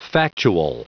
Prononciation du mot factual en anglais (fichier audio)
Prononciation du mot : factual